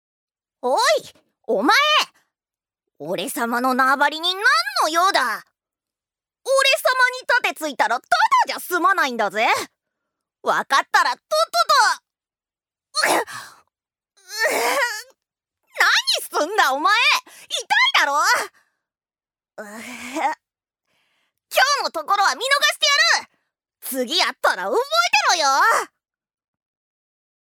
女性タレント
音声サンプル
セリフ５